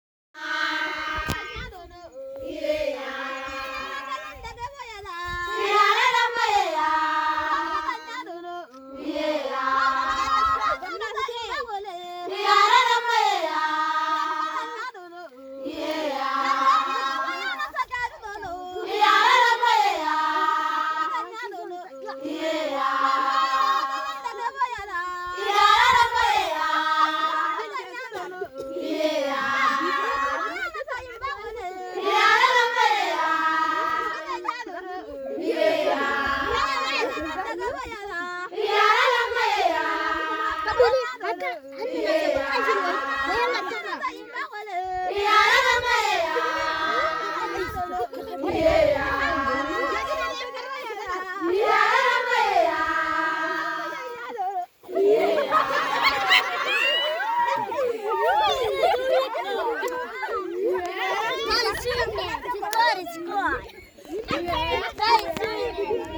Canto hammer 3
Canto-hammer-3.m4a